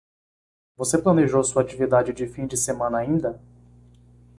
Pronounced as (IPA) /a.t͡ʃi.viˈda.d͡ʒi/